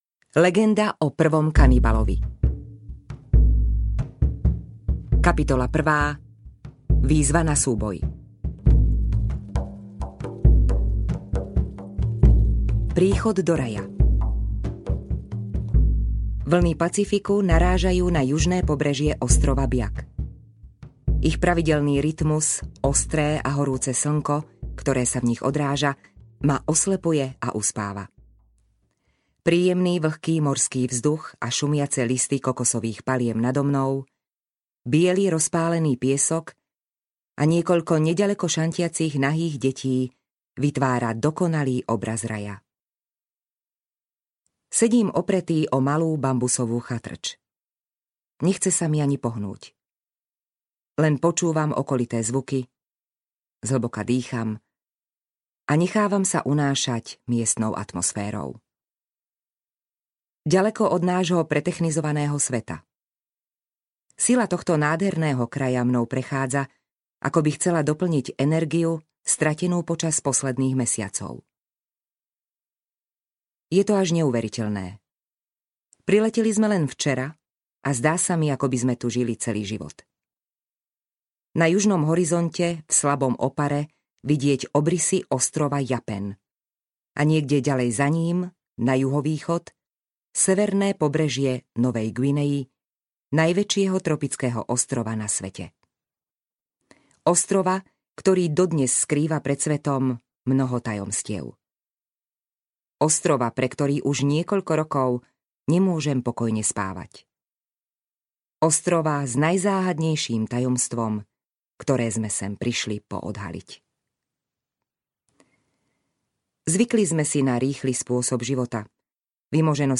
Legenda o prvom kanibalovi audiokniha
Ukázka z knihy